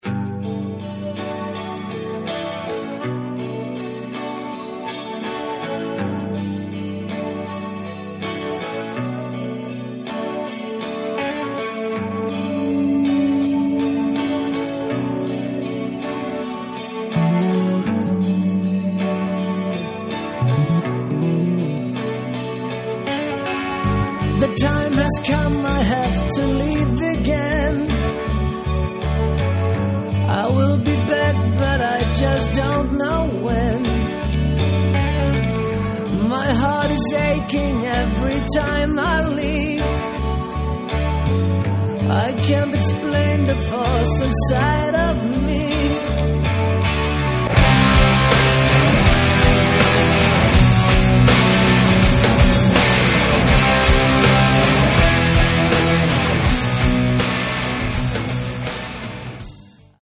Рок
вокал, гитары
бас
ударные
клавишные